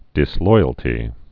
(dĭs-loiəl-tē)